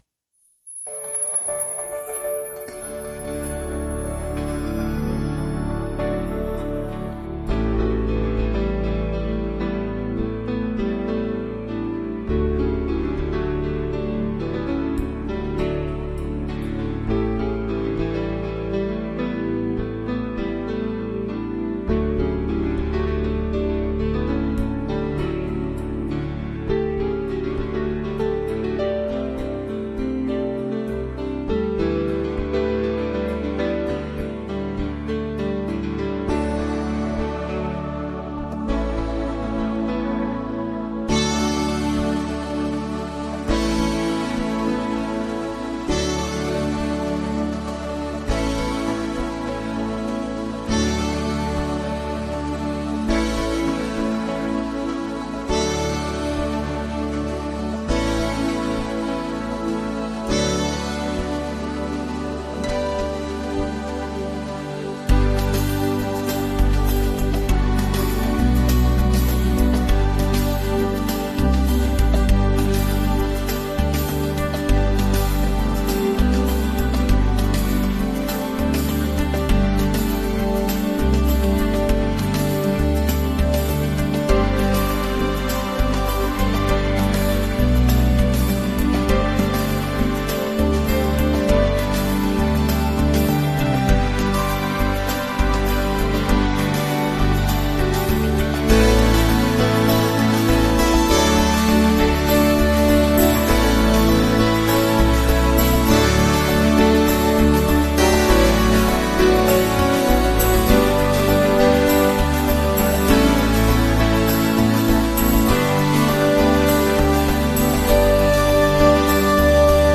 【構成】 ・イントロは、優しいアコースティックギターのリフから始まり、徐々にシンセサイザーのパッド音が加わります。
・メインセクションは、ピアノとエレクトリックギターがメロディを奏で、リズミカルなドラムビートが加わります。